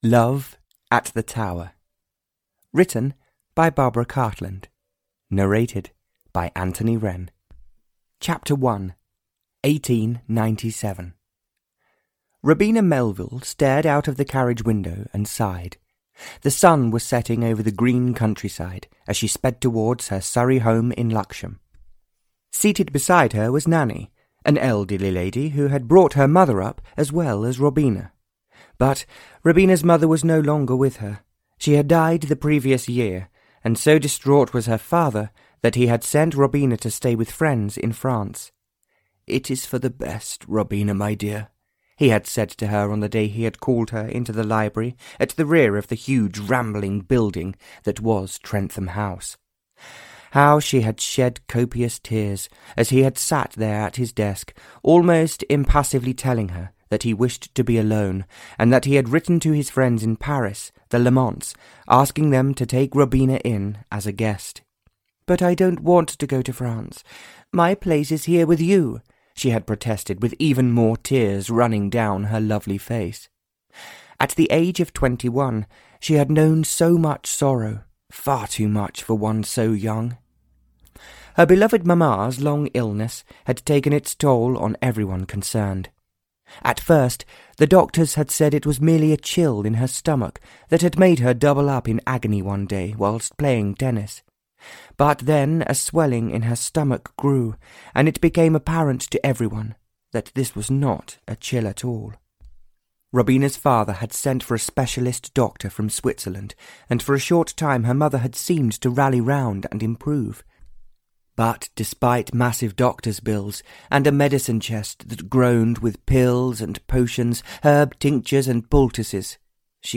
Love at the Tower (EN) audiokniha
Ukázka z knihy